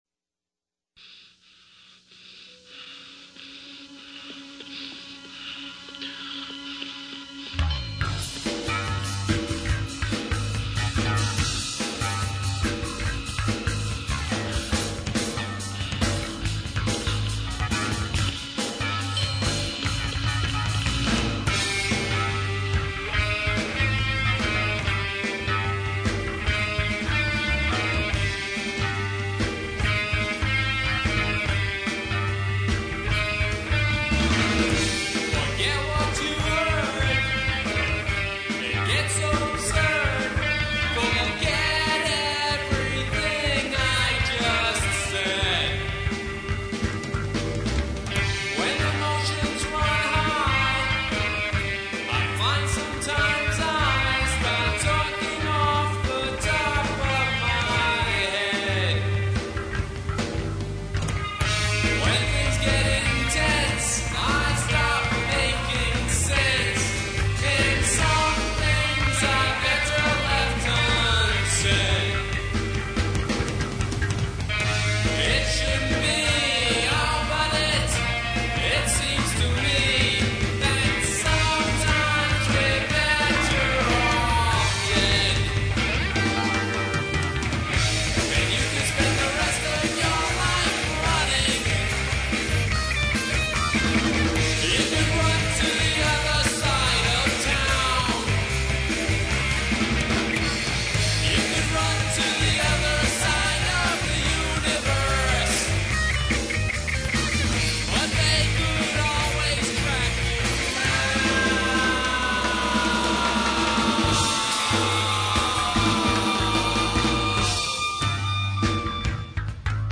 Keyboards, Vocals
Drums, Vocals
Bass, Vocals
Guitar, Lead Vocals